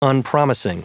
Prononciation du mot unpromising en anglais (fichier audio)
Prononciation du mot : unpromising